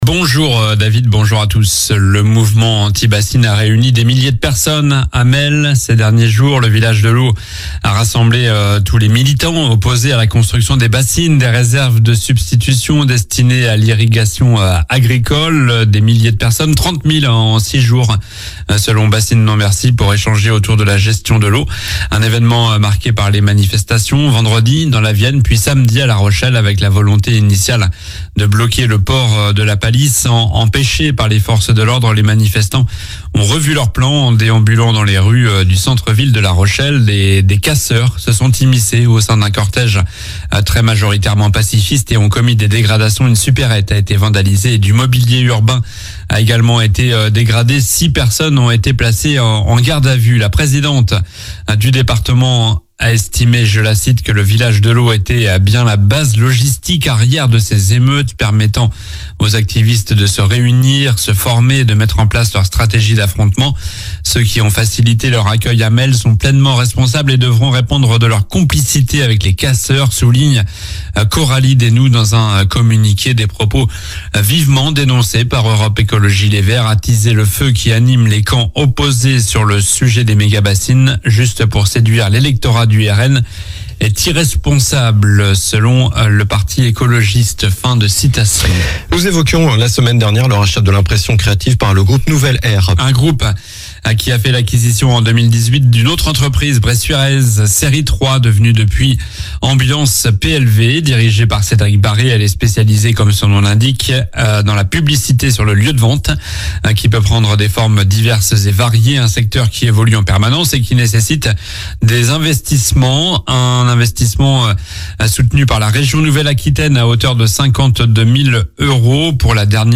Journal du lundi 22 juillet (matin)